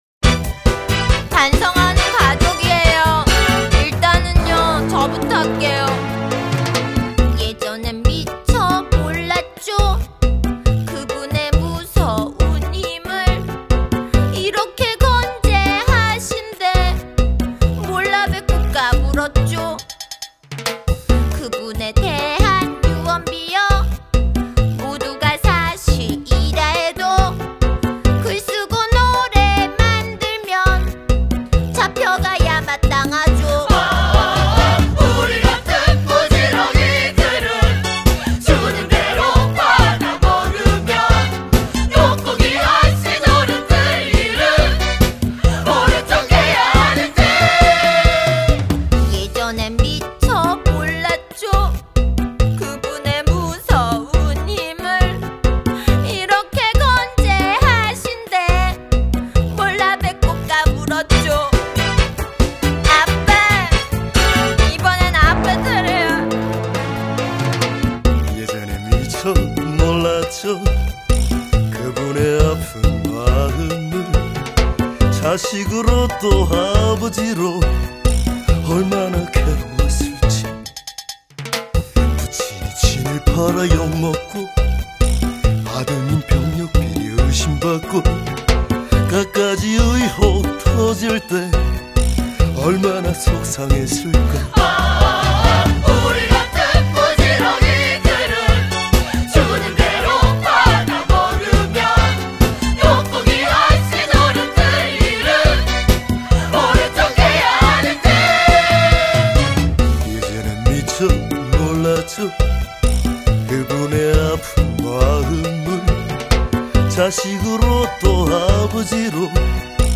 먼저 딸아이가 나서서 앳된 목소리로 반성한다.
다음 아빠의 느끼한 목소리가 이어진다.
"여보, 당신도 얼른 빌어" 이번엔 엄마가 바통을 이어받는다.